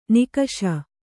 ♪ nikaṣa